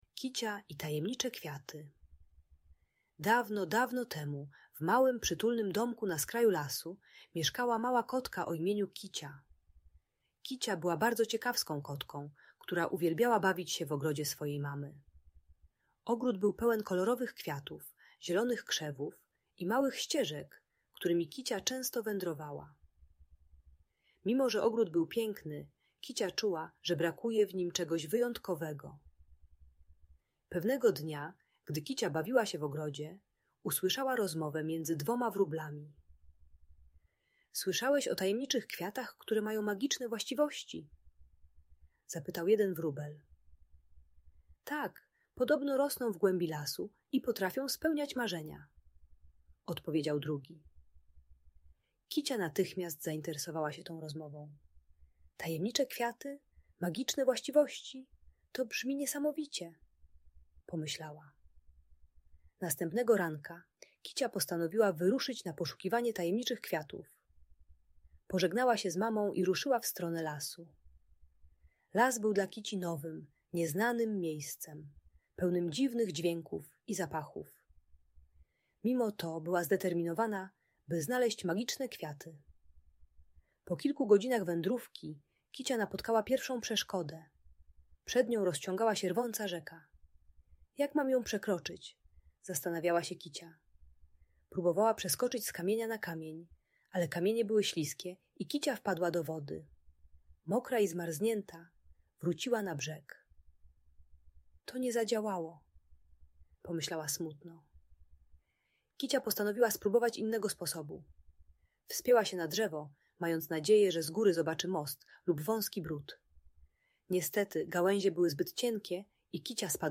Kicia i Tajemnicze Kwiaty - Trening czystości | Audiobajka